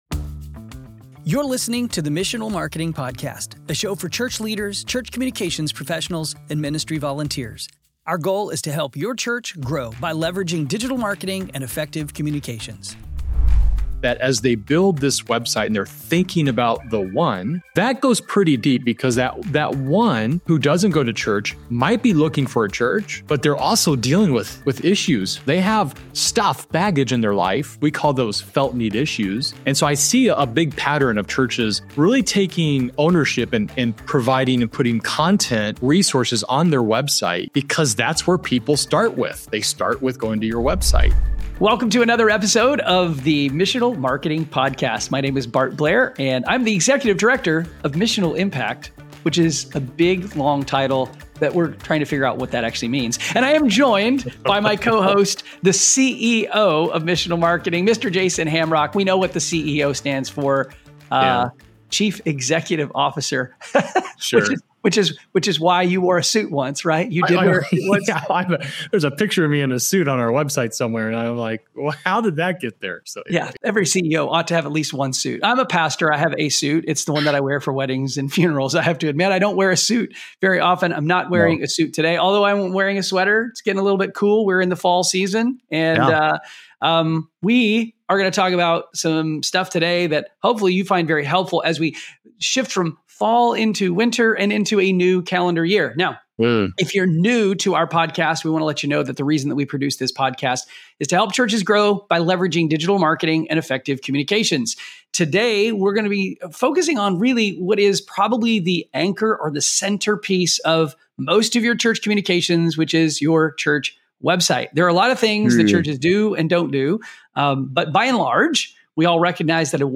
This podcast is comprised of 20 to 45 minute interviews with thought leaders on church marketing and communications.